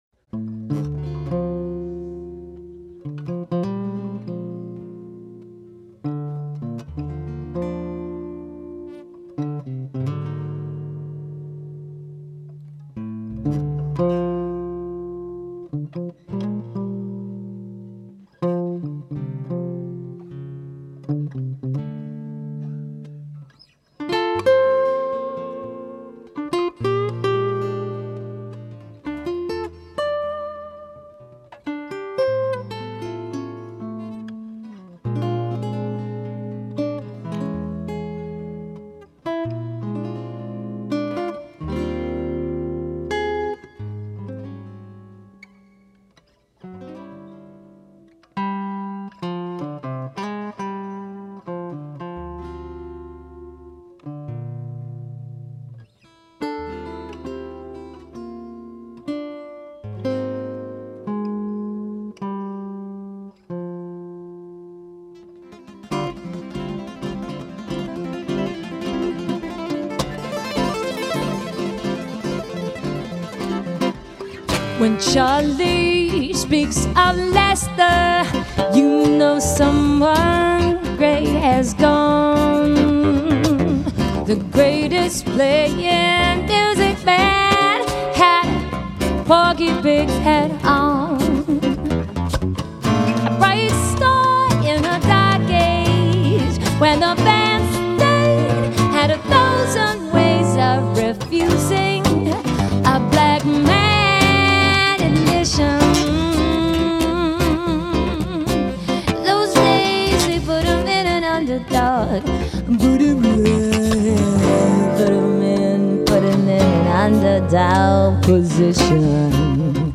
vocals
7 string Tui guitar
guitars